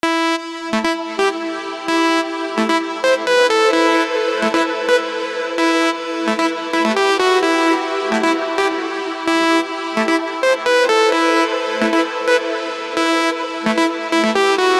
legato_ex_3.mp3